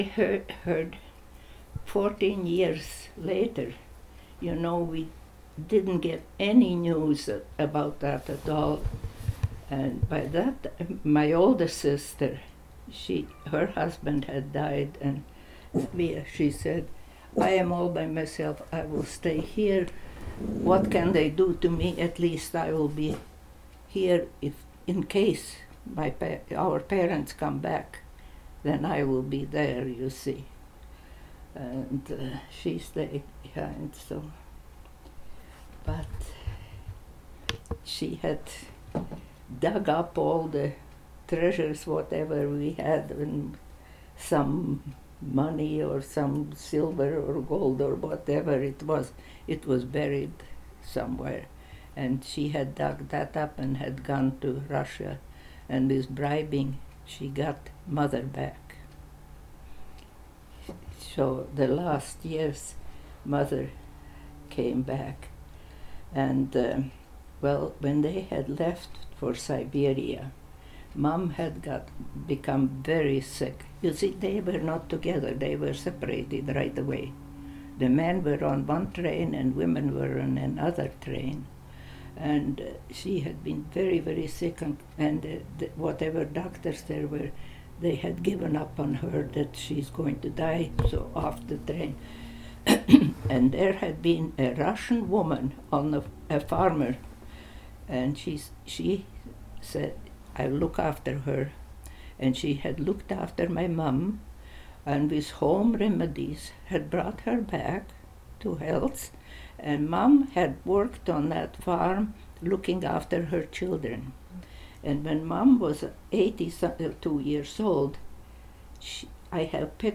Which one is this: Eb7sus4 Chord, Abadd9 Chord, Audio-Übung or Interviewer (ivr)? Interviewer (ivr)